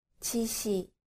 • 지시
• jisi